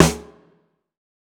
TC3Snare4.wav